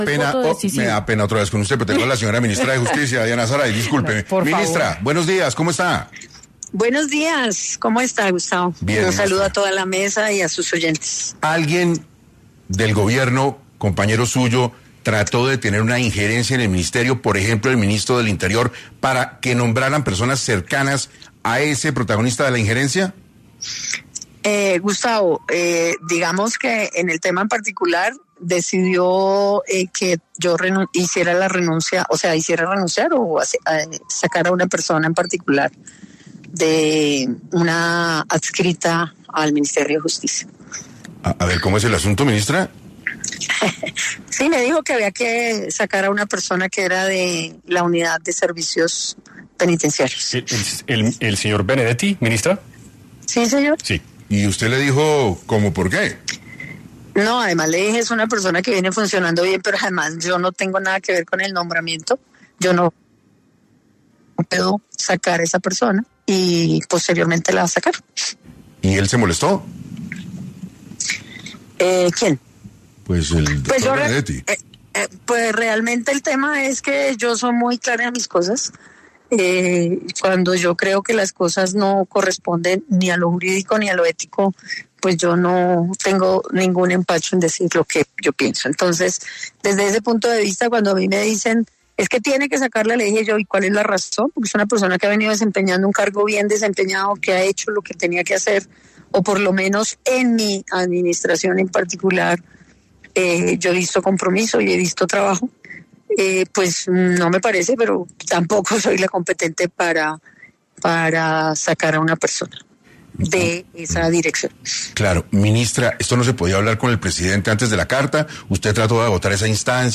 La ministra explicó en 6AM de Caracol Radio que este fue el motivo por el que presentó su renuncia a la cartera de Justicia.